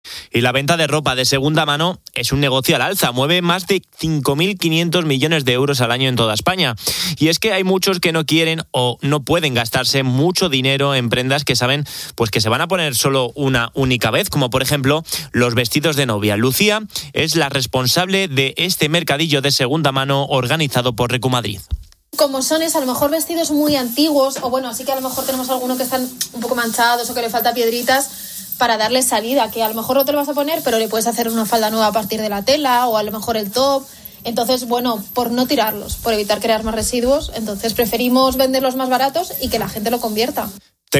Responsable del mercadillo